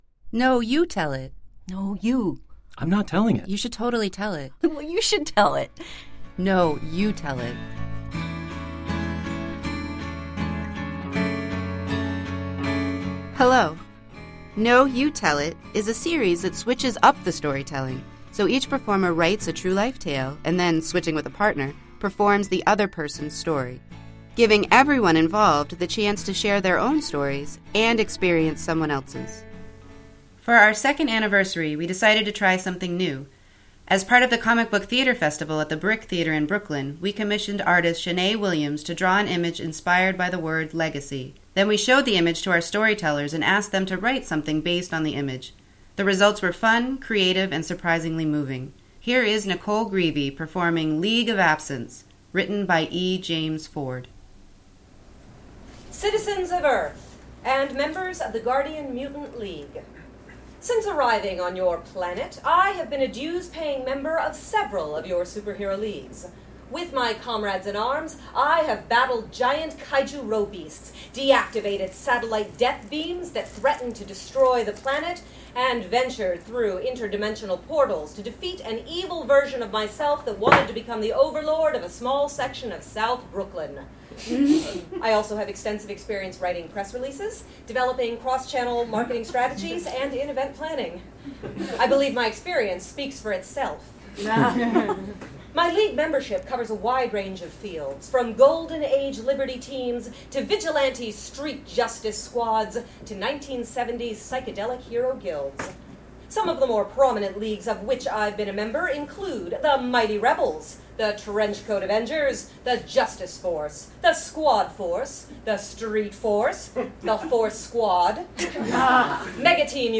Switched-Up Storytelling Series and Podcast